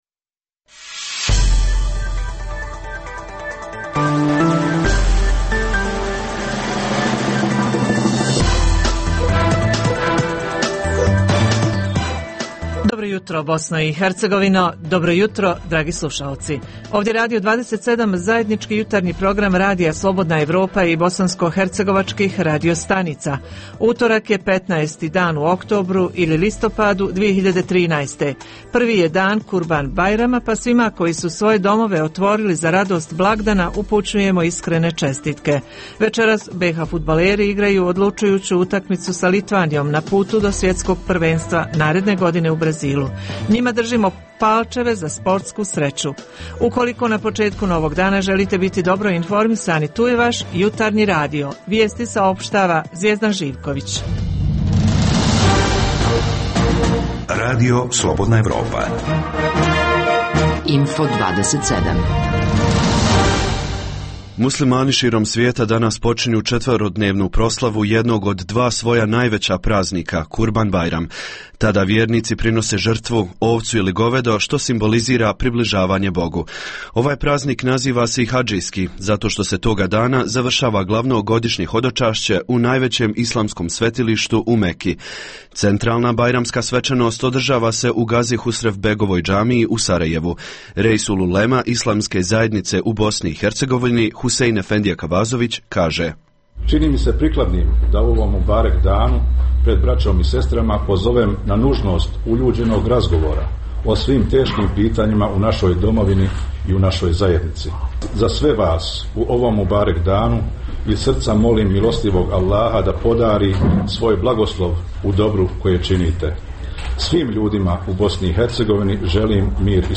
- Svijet internet – redovna rubrika utorkom - Uz tri emisije vijesti slušaoci mogu uživati i u ugodnoj muzici